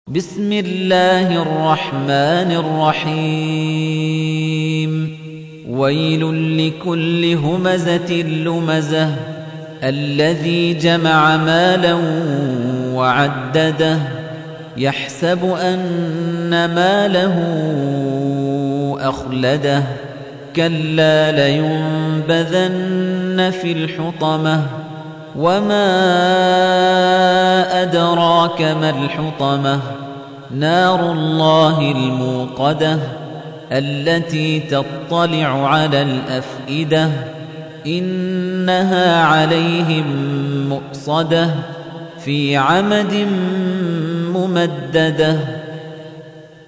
تحميل : 104. سورة الهمزة / القارئ خليفة الطنيجي / القرآن الكريم / موقع يا حسين